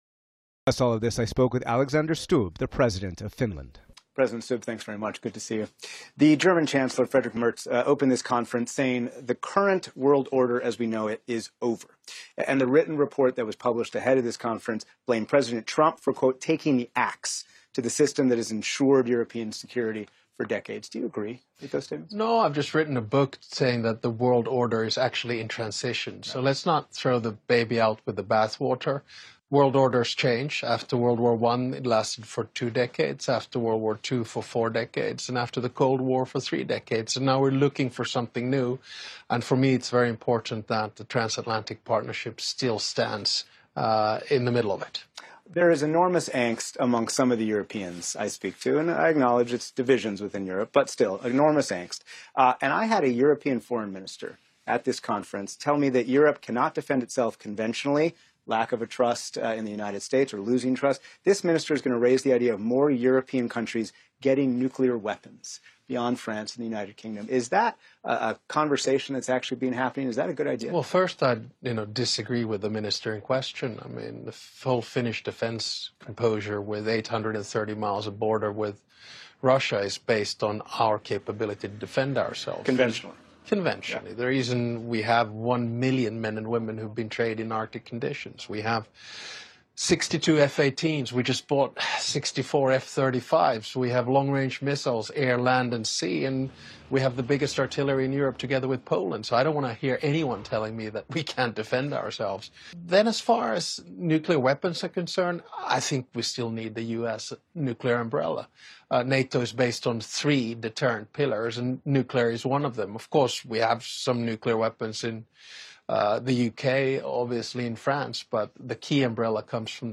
At the Munich Security Conference, Nick Schifrin spoke with Finnish President Alexander Stubb about the future of Europe's relationship with the United States.